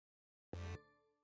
map_open_oneshot_002.wav